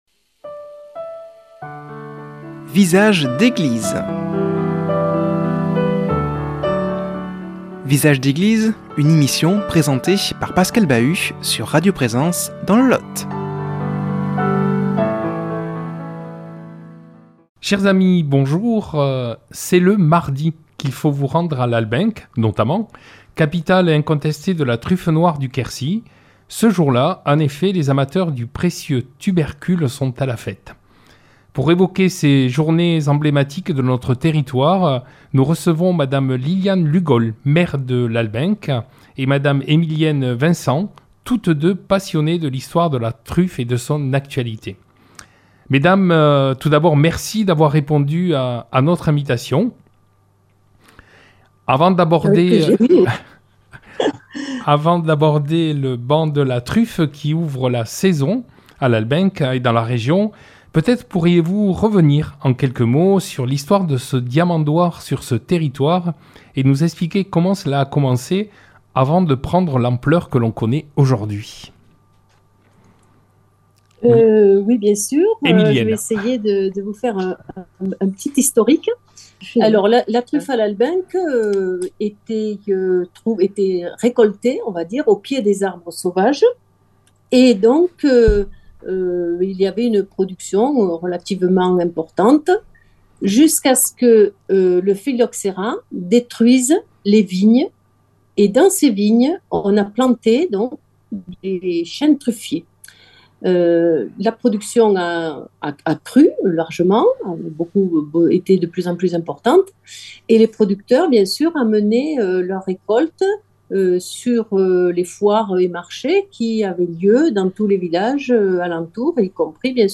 a comme invitées par visio